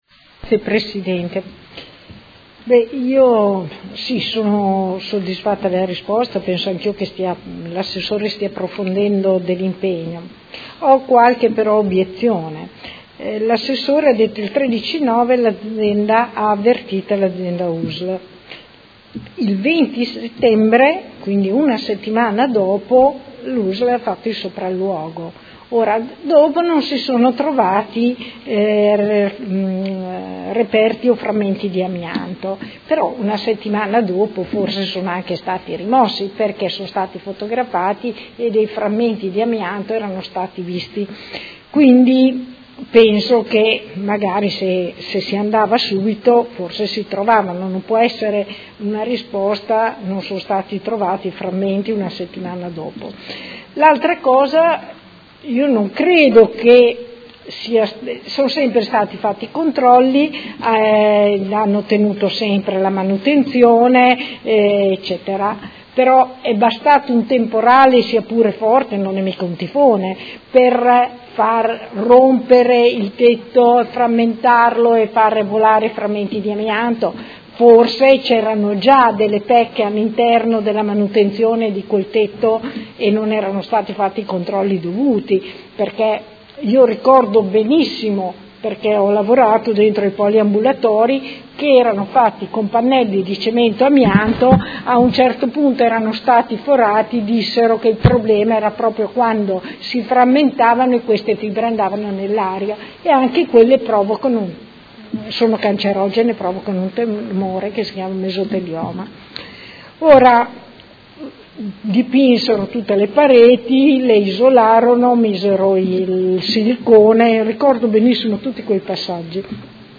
Luigia Santoro — Sito Audio Consiglio Comunale
Seduta del 22/11/2018. Replica al dibattito su interrogazioni sul tema delle Fonderie Cooperative di Modena